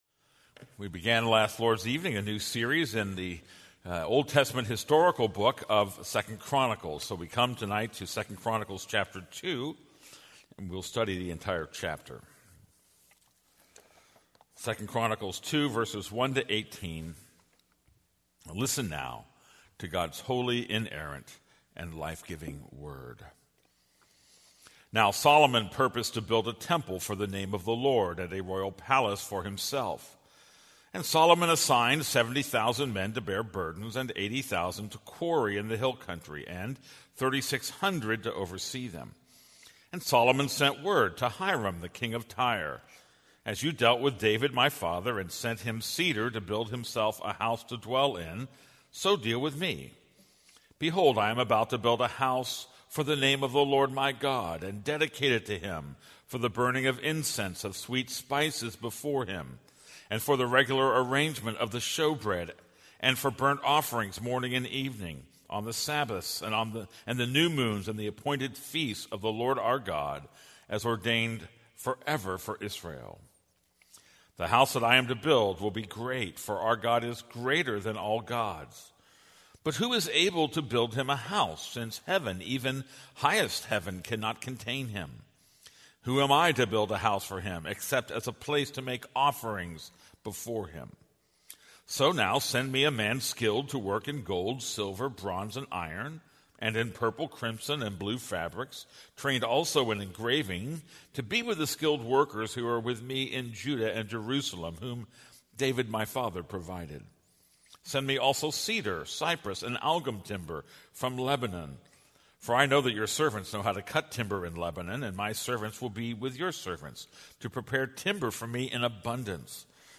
This is a sermon on 2 Chronicles 2:1-18.